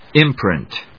音節im・print 発音記号・読み方
/ímprìnt(米国英語)/